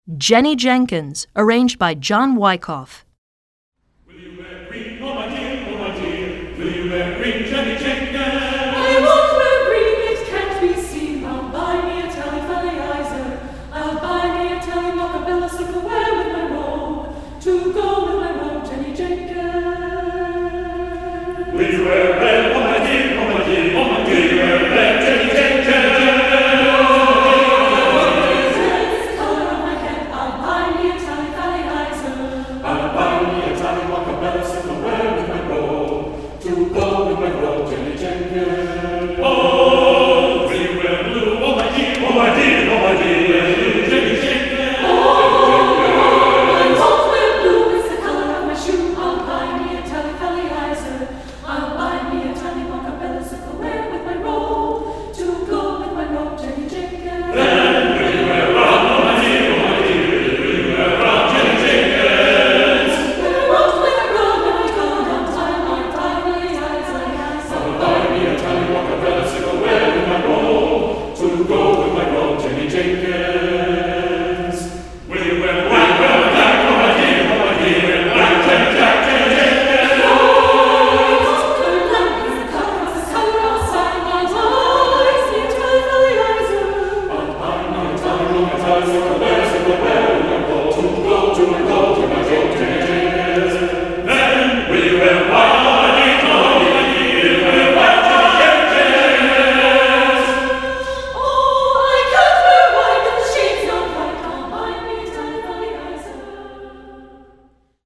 Choeur Mixte (SATB)